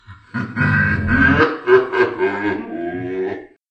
laugh_p0phoxS.mp3